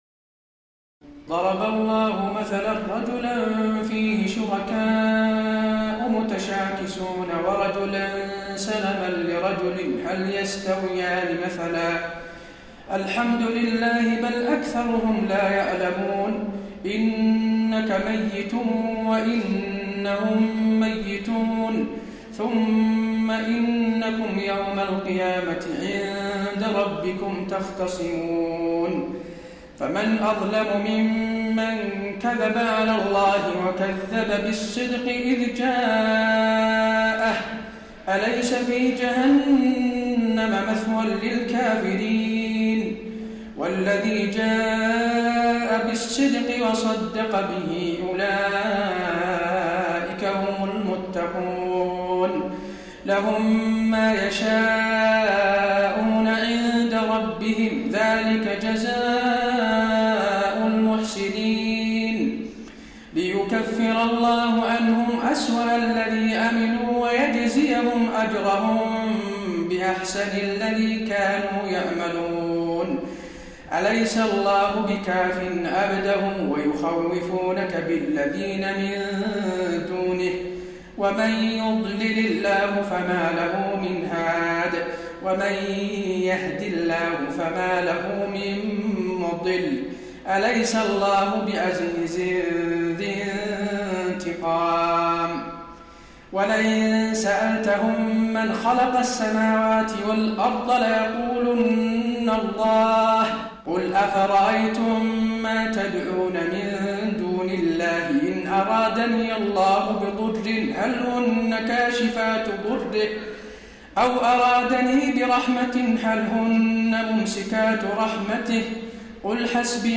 تراويح ليلة 23 رمضان 1432هـ من سور الزمر (29-75) و غافر (1-40) Taraweeh 23 st night Ramadan 1432H from Surah Az-Zumar and Ghaafir > تراويح الحرم النبوي عام 1432 🕌 > التراويح - تلاوات الحرمين